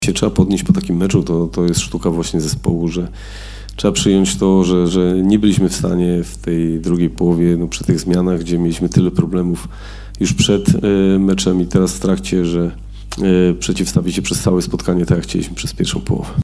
-Mówił selekcjoner, Michał Probierz